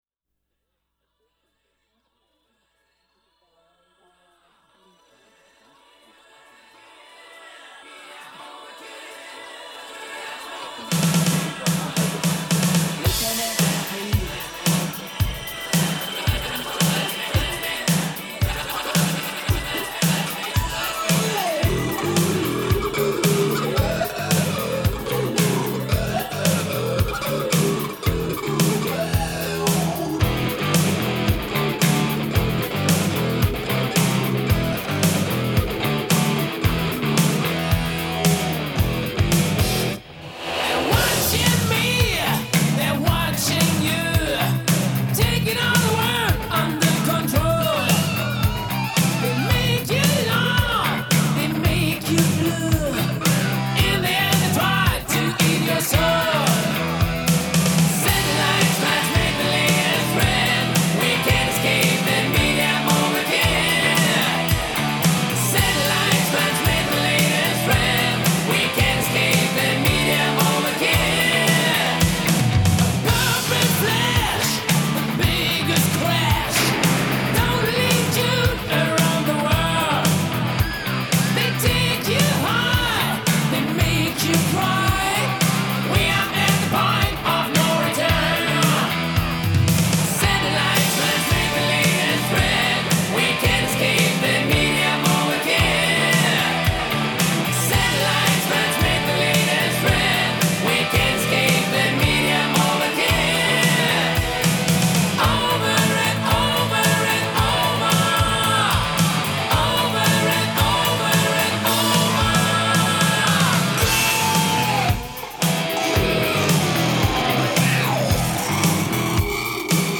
At first, I was a little dismayed by the production.